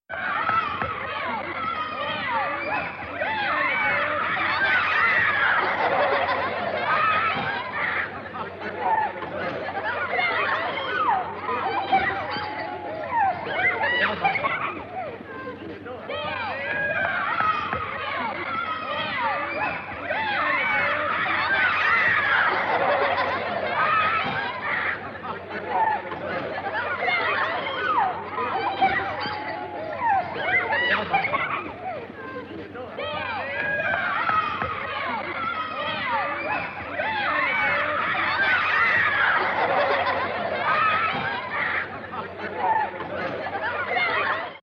На этой странице собраны звуки, характерные для детского лагеря: смех ребят, шум игр на свежем воздухе, вечерние посиделки с гитарой и другие атмосферные моменты.
Дети резвятся на улице